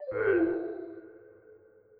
Effects_r1_Die.wav